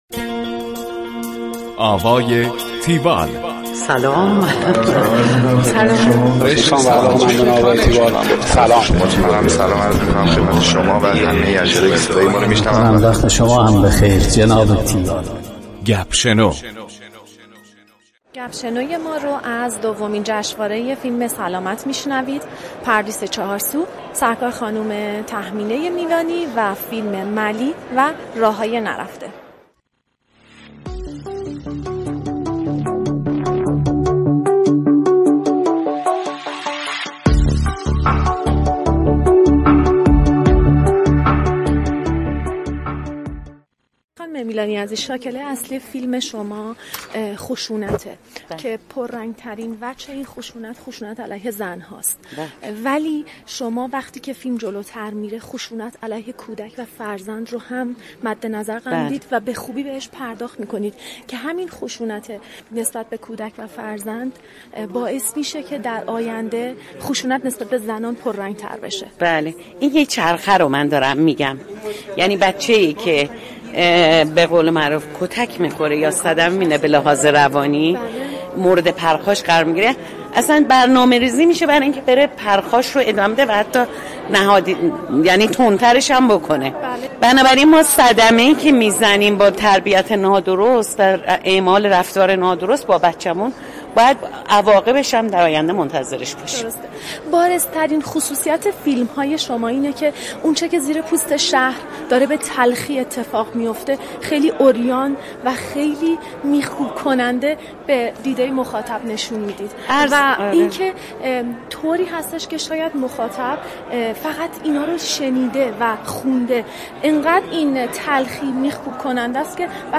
گفتگوی تیوال با تهمینه میلانی